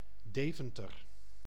Deventer (Dutch: [ˈdeːvəntər]